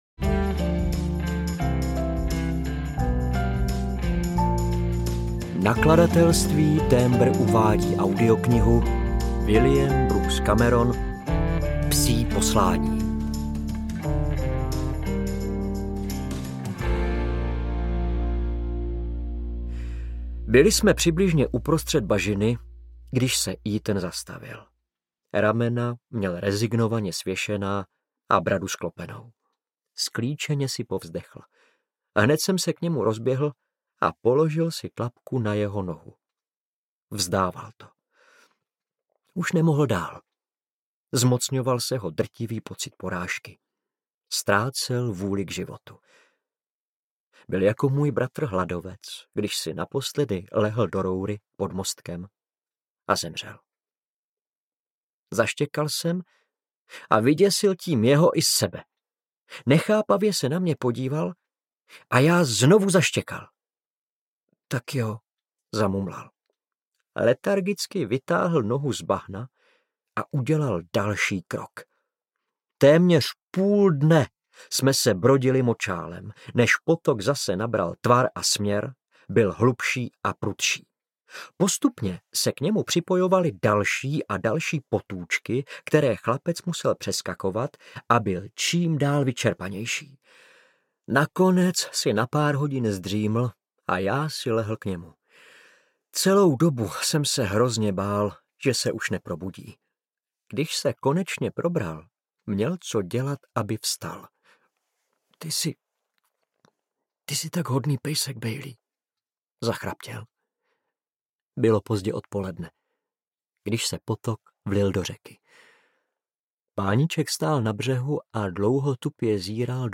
Psí poslání audiokniha
Ukázka z knihy
• InterpretJaroslav Plesl